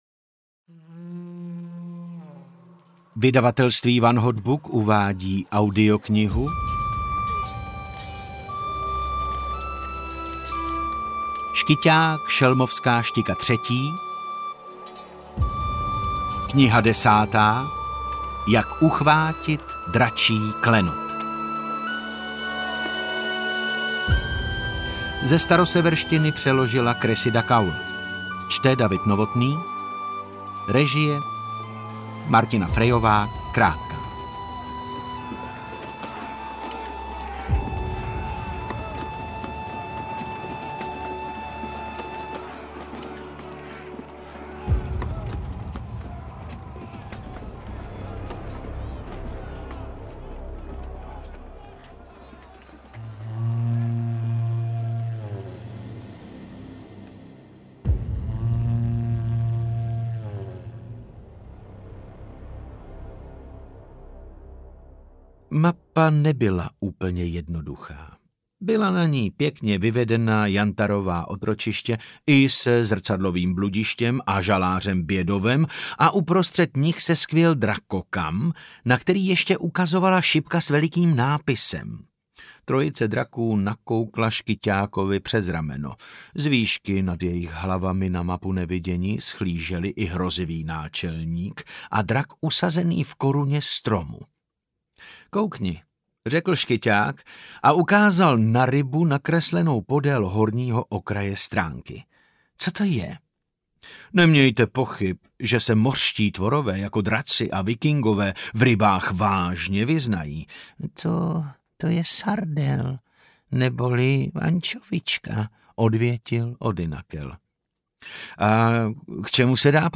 Jak uchvátit dračí klenot audiokniha
Ukázka z knihy